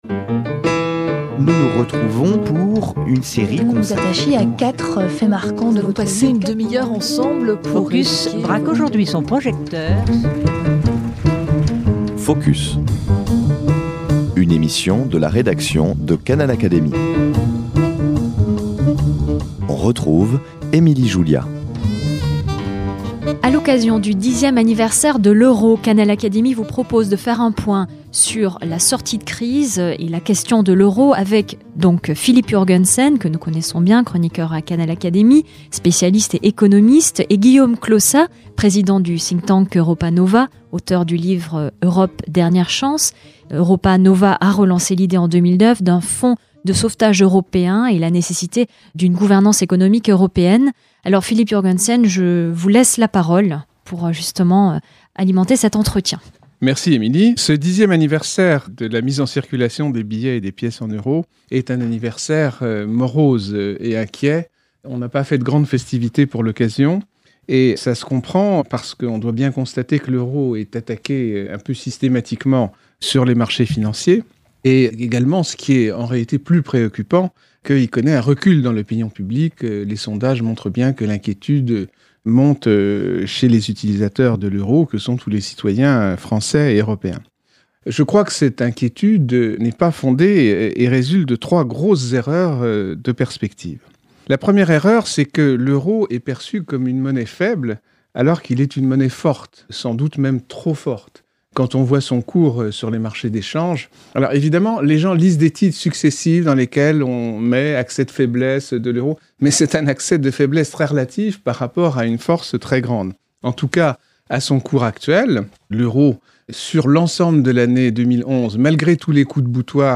dans cet entretien qui rectifient au passage quelques jugements erronés et avancent quelques bonnes solutions pour l’avenir...